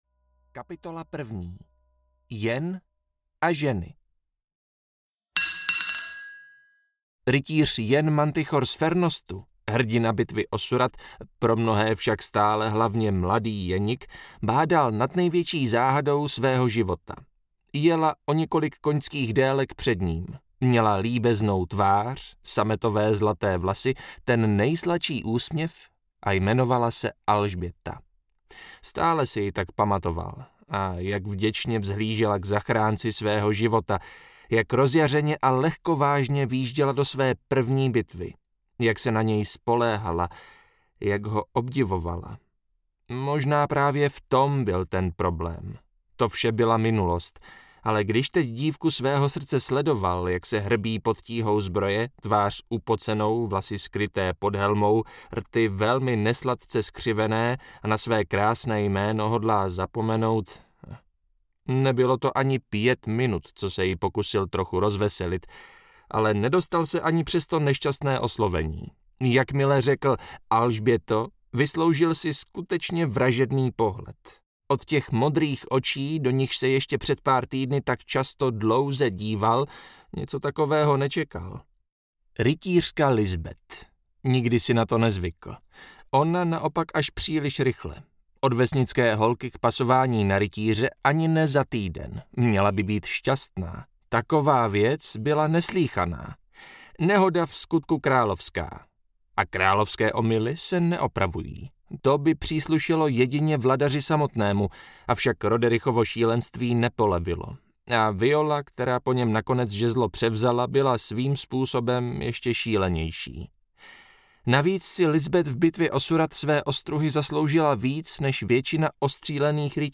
Zdvořilostní návštěva audiokniha
Ukázka z knihy